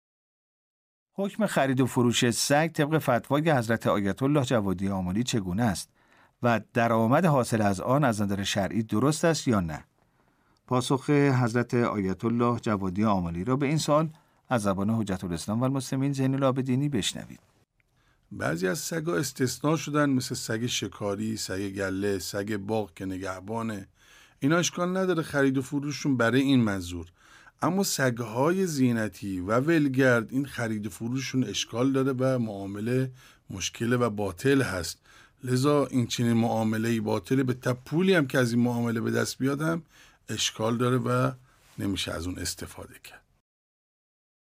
پاسخ نماینده دفتر آیت الله العظمی جوادی آملی